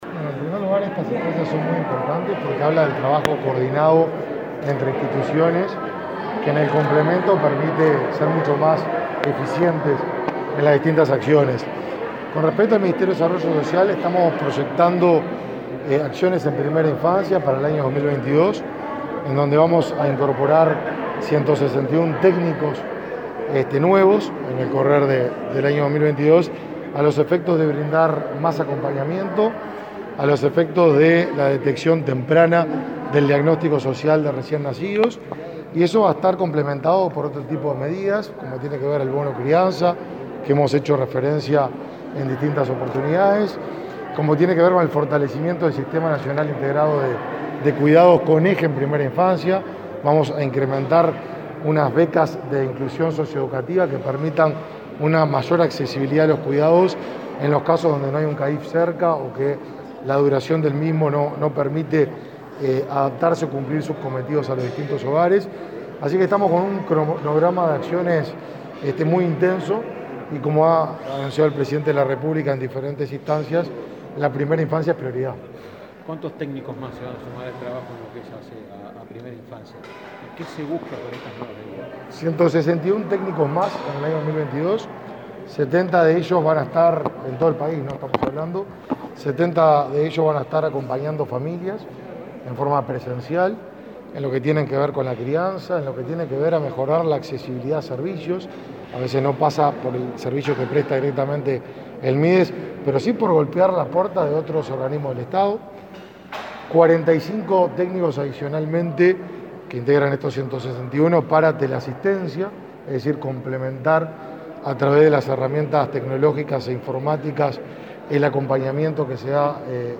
Declaraciones a la prensa del ministro de Desarrollo Social, Martín Lema
Declaraciones a la prensa del ministro de Desarrollo Social, Martín Lema 19/11/2021 Compartir Facebook X Copiar enlace WhatsApp LinkedIn El ministro de Desarrollo Social, Martín Lema, participó este viernes 19 en Montevideo, de la instalación del Consejo Consultivo Honorario de los Derechos del Niño y el Adolescente y, luego, dialogó con la prensa.